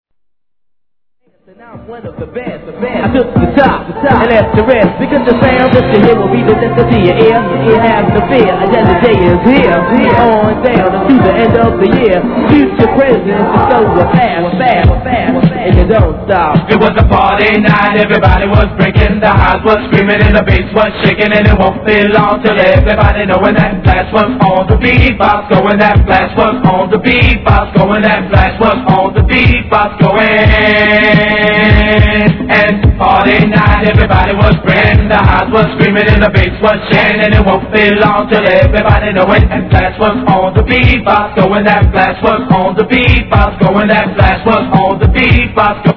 HIP HOP/R&B
伝説のライブ音源収録！！